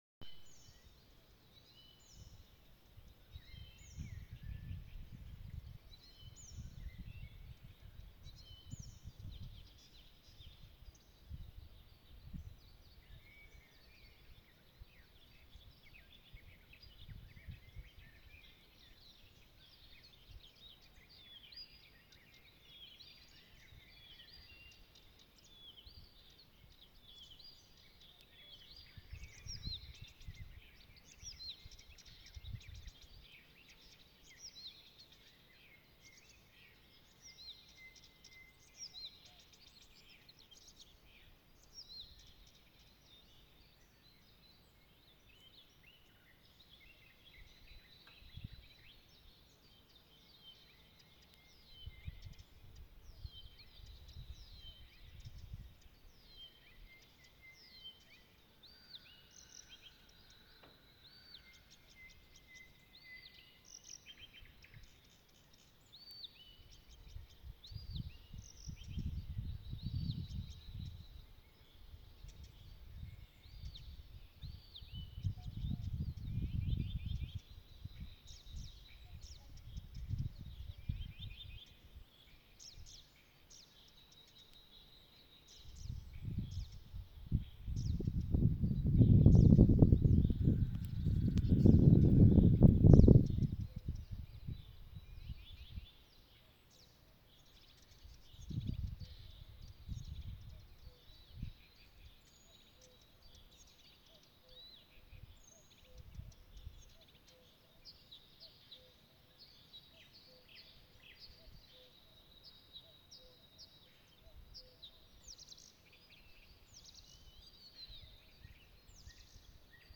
Birds -> Warblers ->
Blyth’s Reed Warbler, Acrocephalus dumetorum
StatusSinging male in breeding season